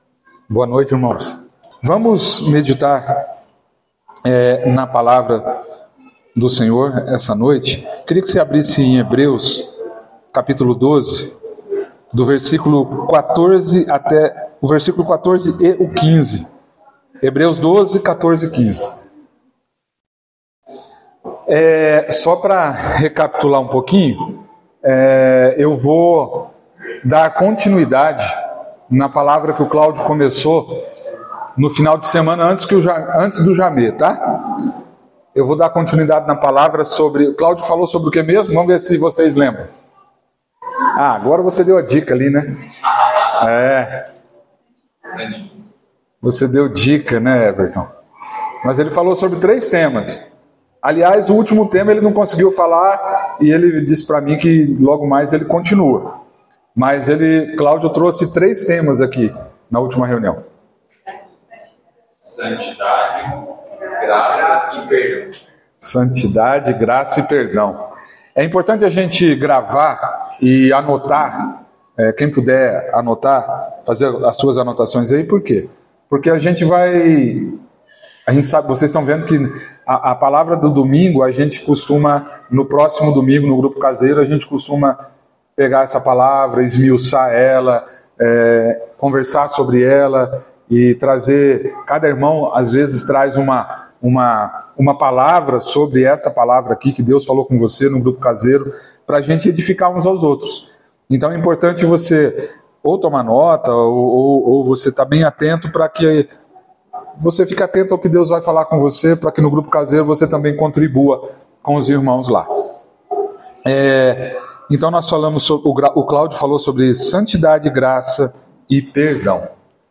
Palavra ministrada